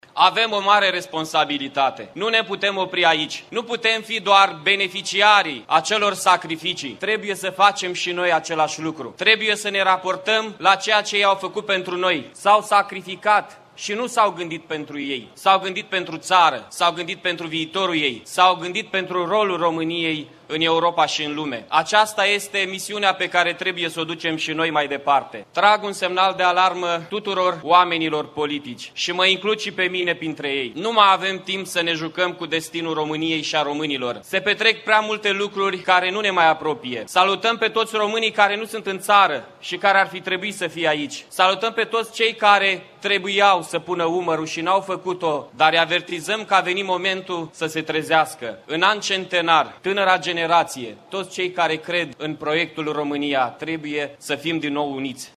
Aproximativ 5.000 de persoane au participat, astăzi, în Piaţa Unirii din Iaşi la manifestările organizate pentru a marca importanţa Unirii de la 1859.
La rândul său, Primarul Iaşului, Mihai Chirica a îndemnat clasa politică la o meditaţie profundă care să determine elaborarea unui proiect menit să-i unească pe toţi românii: